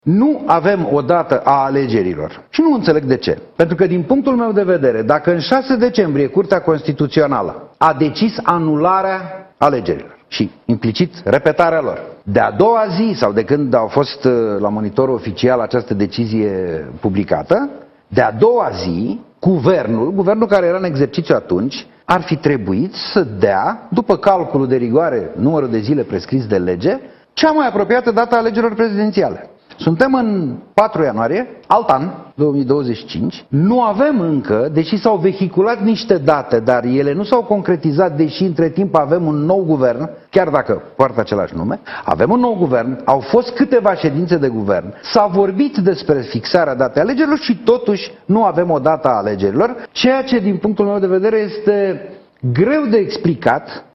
Fostul președinte al PNL a spus sâmbătă seara, la Digi 24, că e nemulţumit: în primul rând pentru că nu a fost stabilită încă nicio dată pentru alegeri, iar în al doilea rând pentru că nu a existat un vot unanim din partea Coaliţiei pentru candidatura sa.